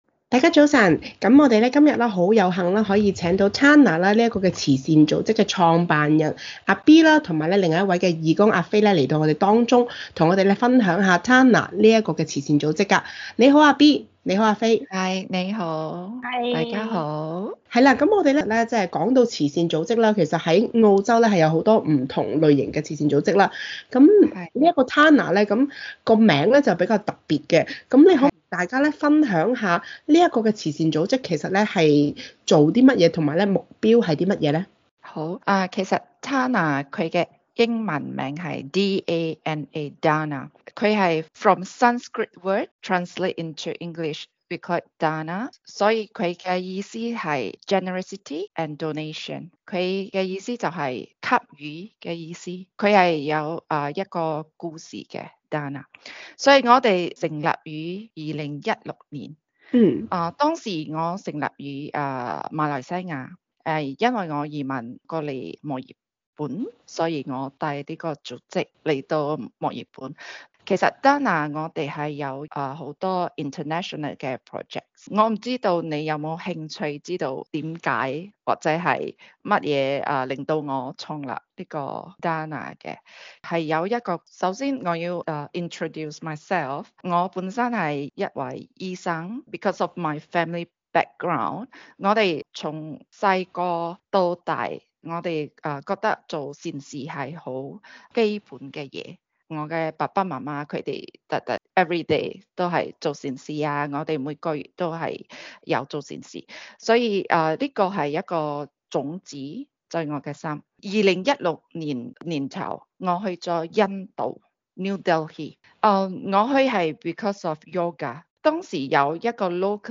can_community_interview_dana_podcast.mp3